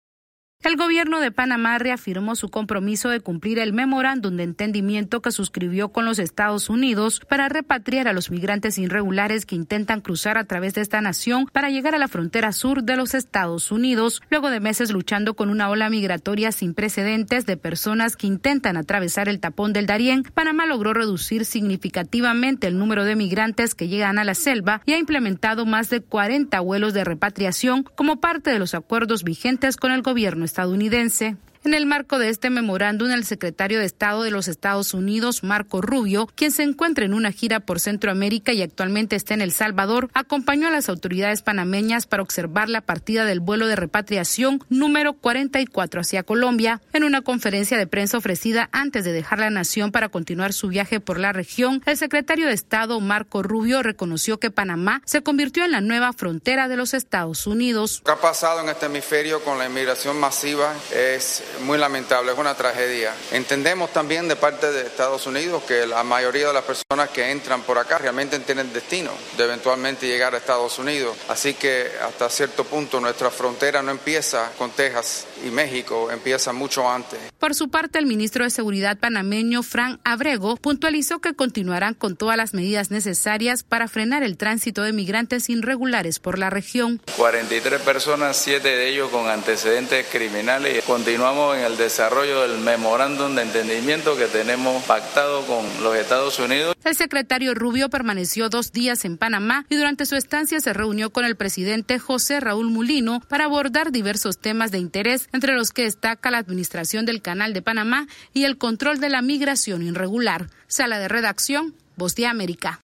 Panamá reiteró ante el secretario estadounidense de Estado, Marco Rubio, su compromiso de deportar migrantes irregulares que intentan cruzar por esta nación rumbo a los Estados Unidos. Esta es una actualización de nuestra Sala de Redacción.